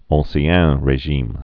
ɴ-syăɴ rā-zhēm)